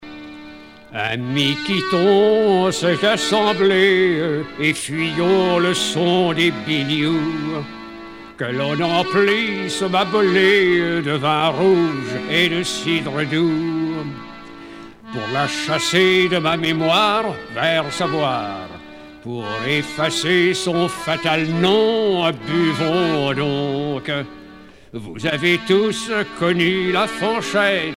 Pièce musicale éditée